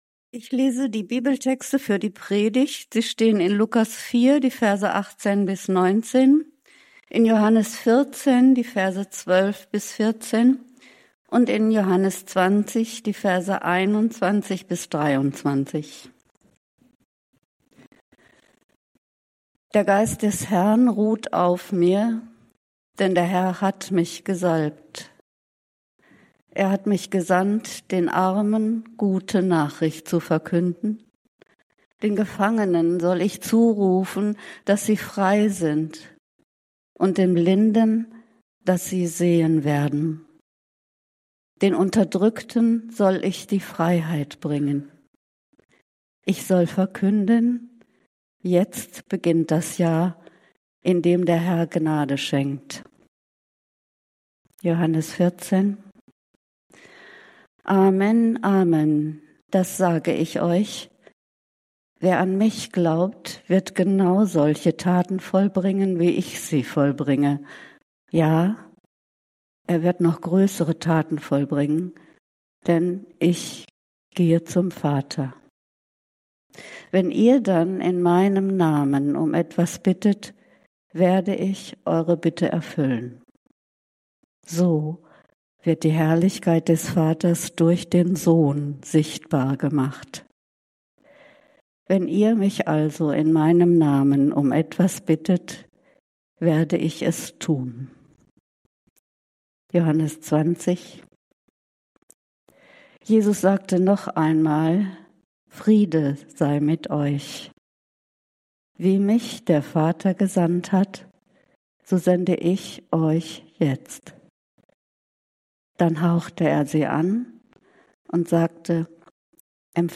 Die Botschaft deines Lebens: Dem Evangelium Raum geben ~ Berlinprojekt Predigten Podcast